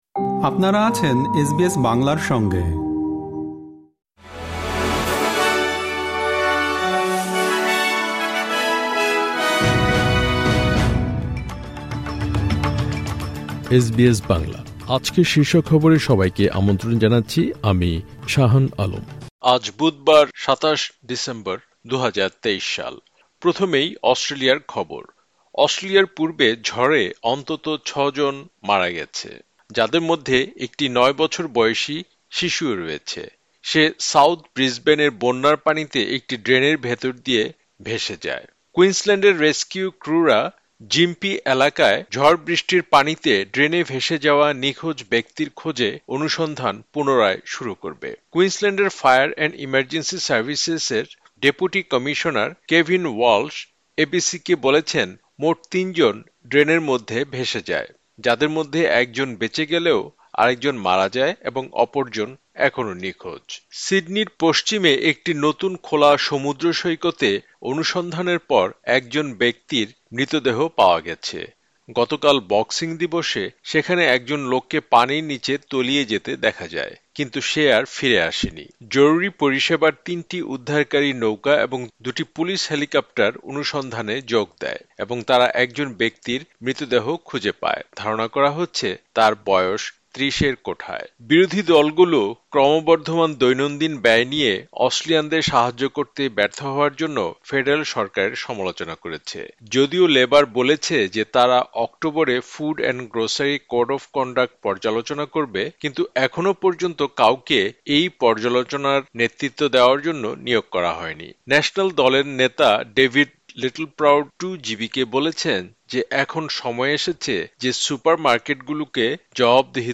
এসবিএস বাংলা শীর্ষ খবর: ২৭ ডিসেম্বর, ২০২৩